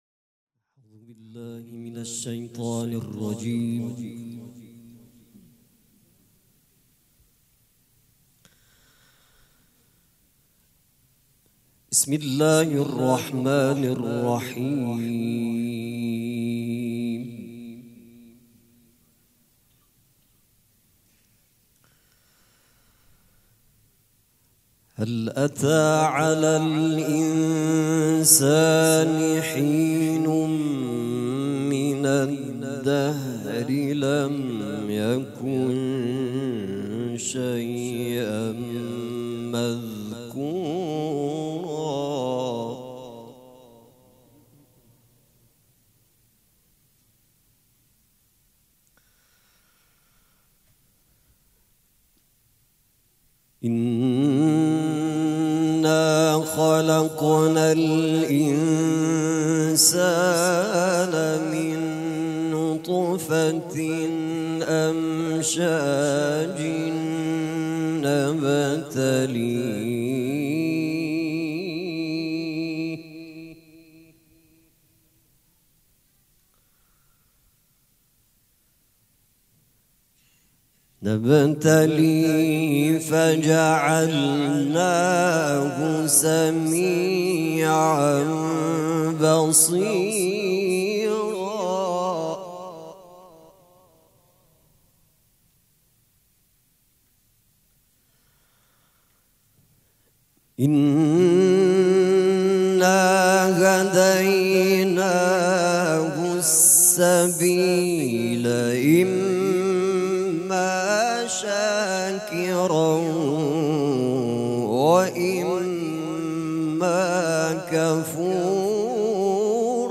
قرائت قرآن
قرائت قرآن کریم
مراسم عزاداری شب سوم